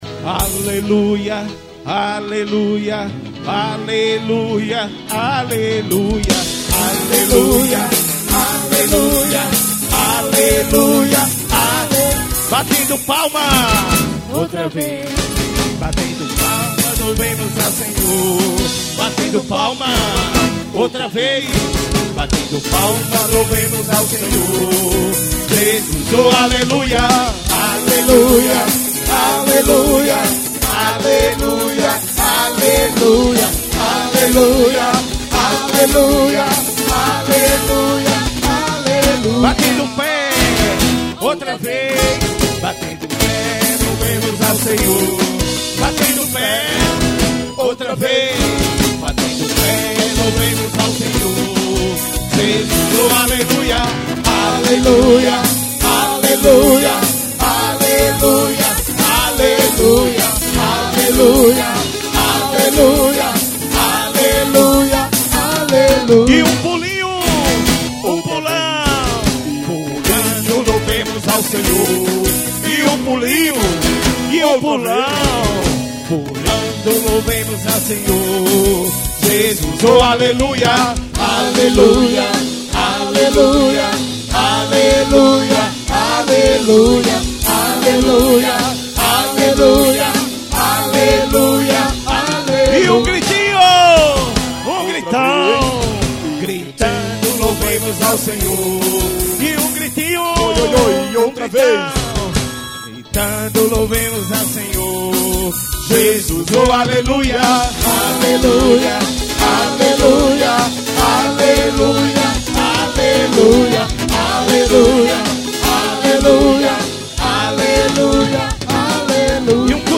Católica.